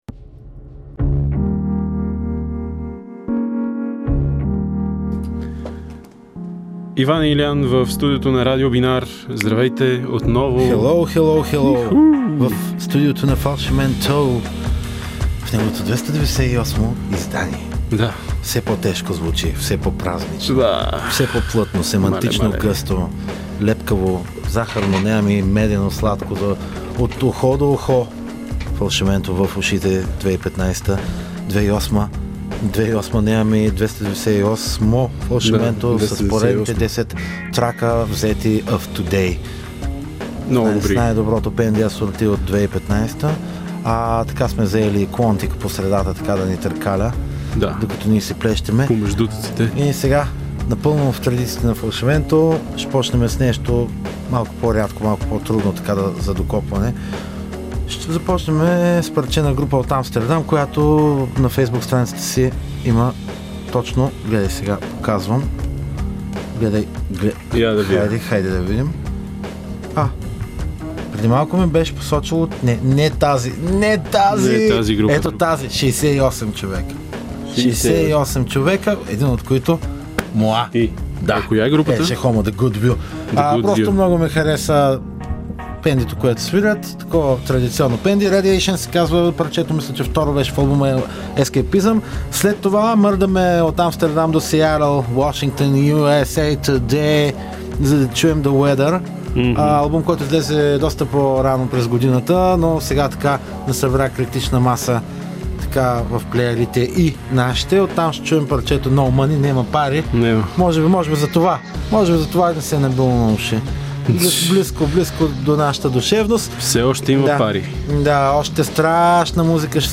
Все по-тежки, празничи и лепкави.